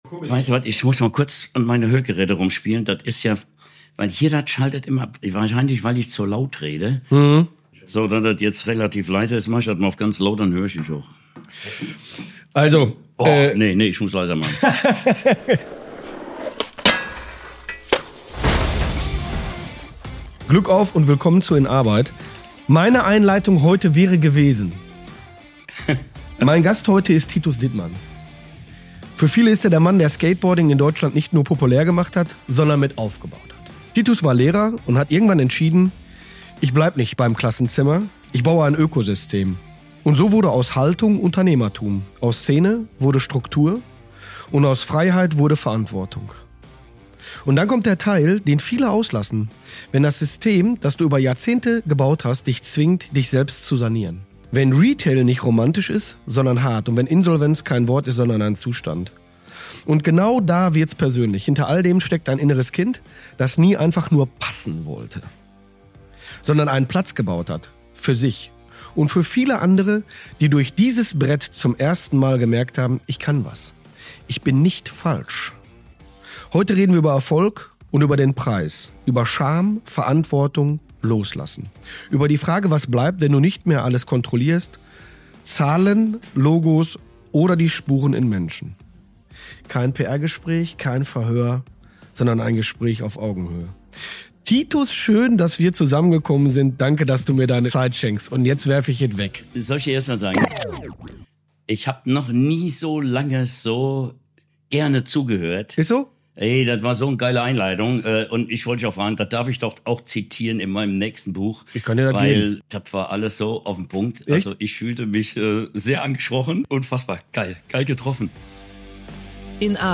Ein Gespräch über Ehrlichkeit mit sich selbst, über den Unterschied zwischen Fleiß und brennendem Herzen, über ADHS als Gabe statt Diagnose – und über die Frage, ob man Spuren hinterlässt oder nur Zahlen.